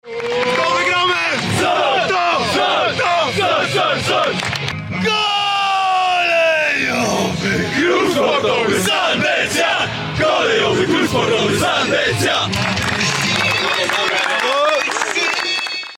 Na stadionie przy ul. Kilińskiego w Nowym Sączu słychać było radość zawodników, która nawiązywała do tradycji drużyny